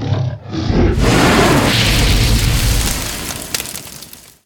fireball.ogg